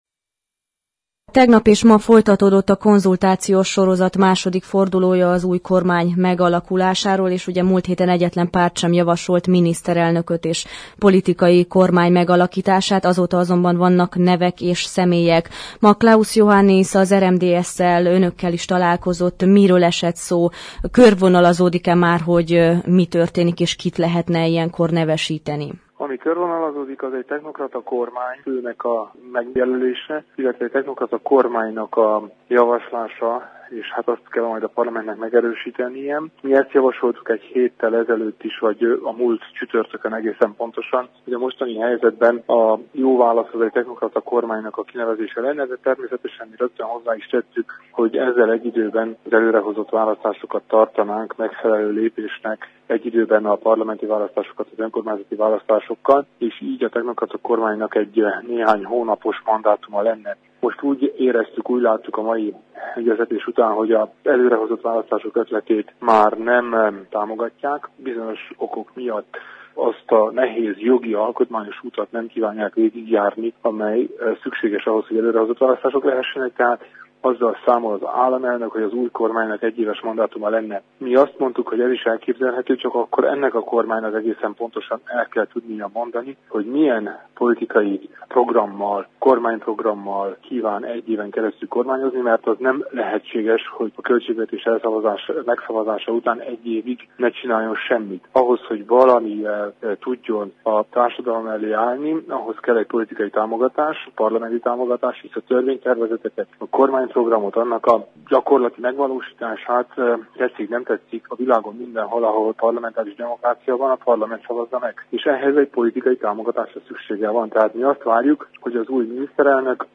A mai egyeztetésekről Kelemen Hunor szövetségi elnök nyilatkozott rádiónknak.